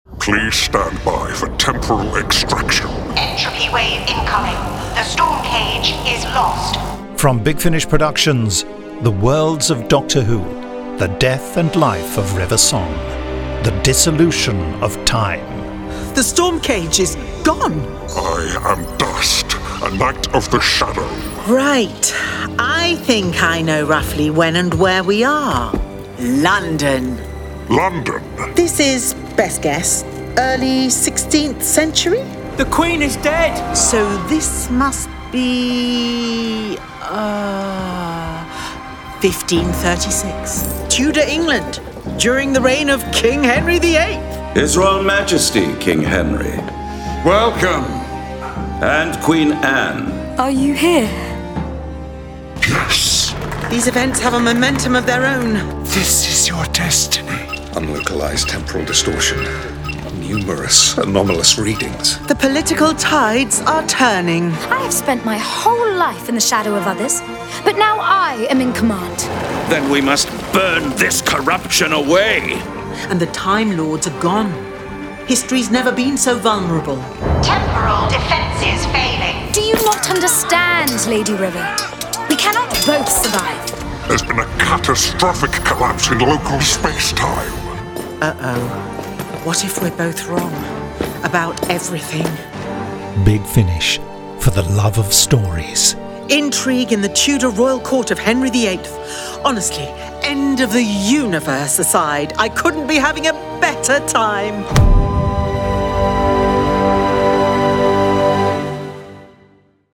Starring Alex Kingston